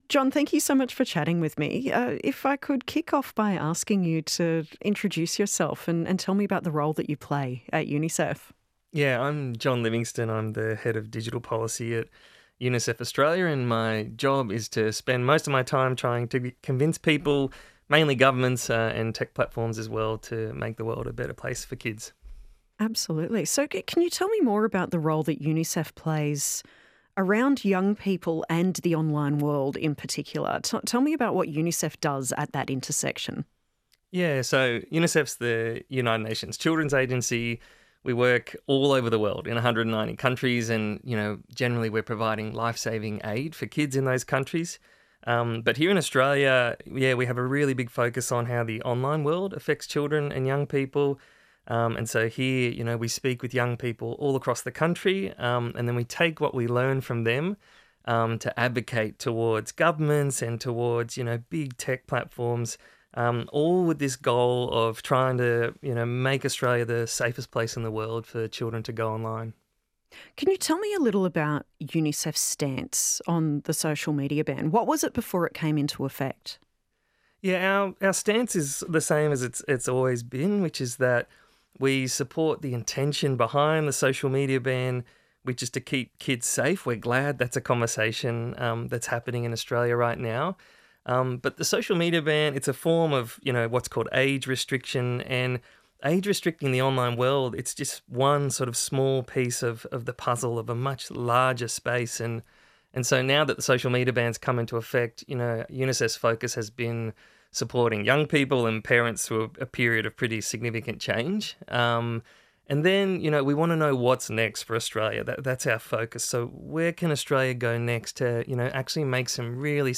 ---------- This is the first edition of our 'on the down-low' series -- longer standalone interviews with interesting people in the tech world.